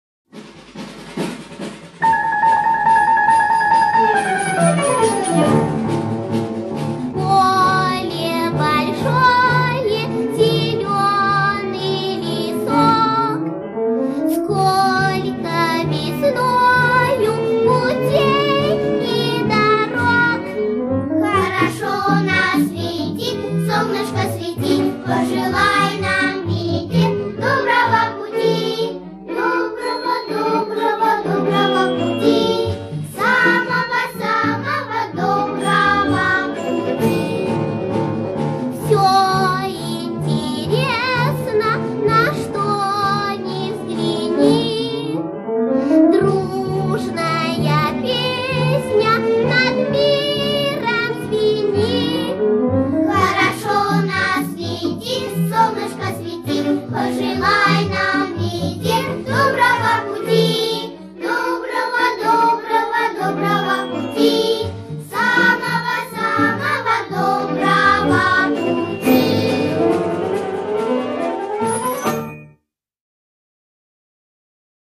а подпевает ей детский хор.